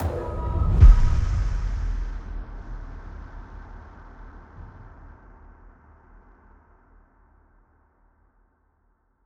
impact_06.wav